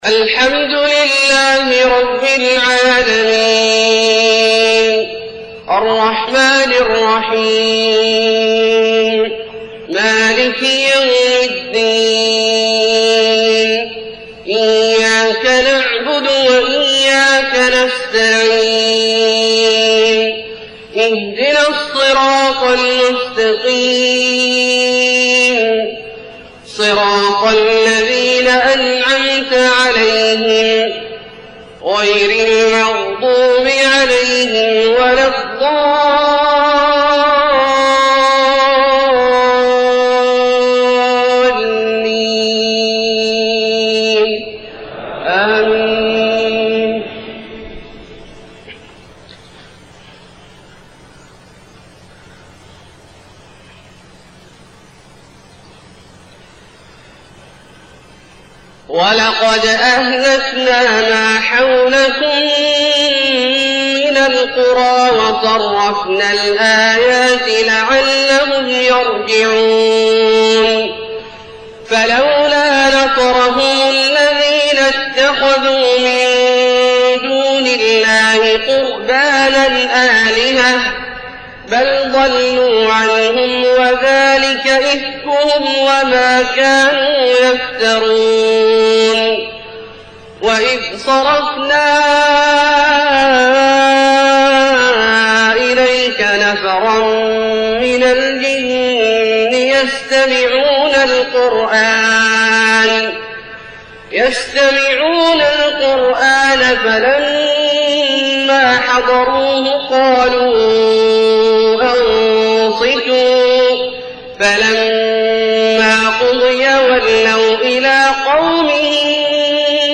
صلاة المغرب1-8-1431 من سورة الأحقاف {27-35} > ١٤٣١ هـ > الفروض - تلاوات عبدالله الجهني